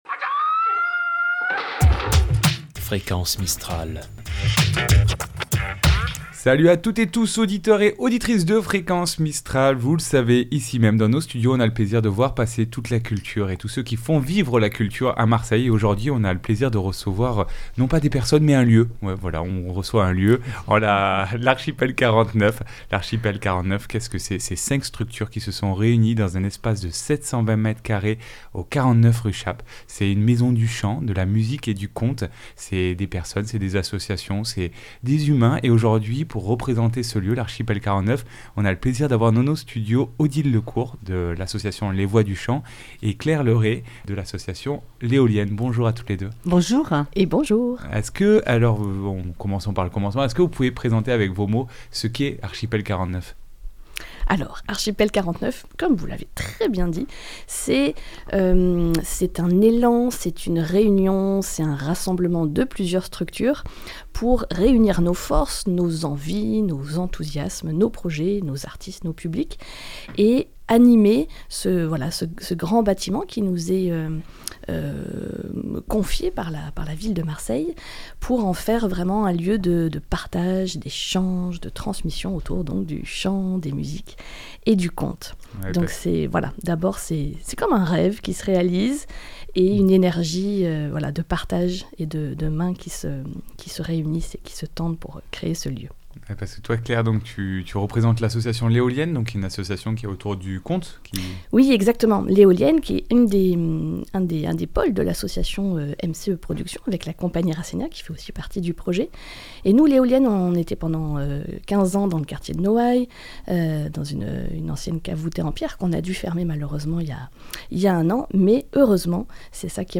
Itw Archipel 49 .mp3 (26.04 Mo)